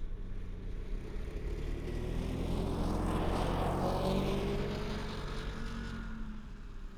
Internal Combustion Snowmobile Description Form (PDF)
Internal Combustion Subjective Noise Event Audio File (WAV)